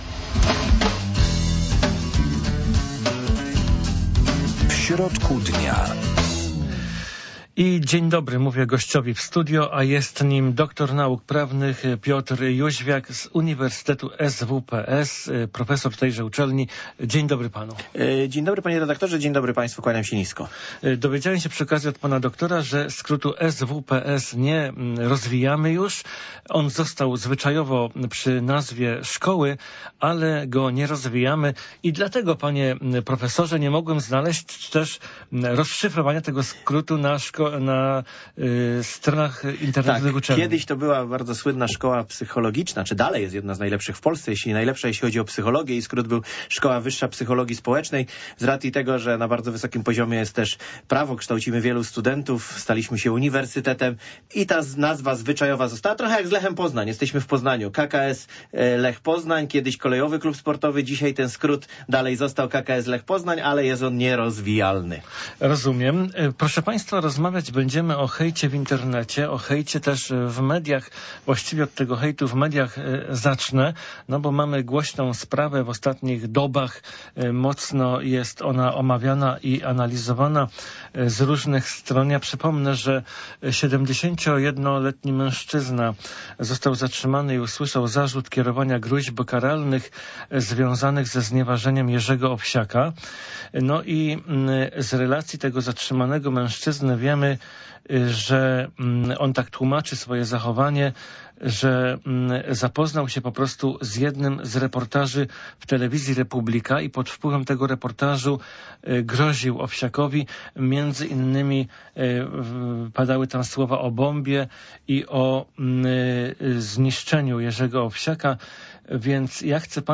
Zapraszamy do rozmowy w audycji „W środku dnia”.